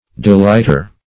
Delighter \De*light"er\, n.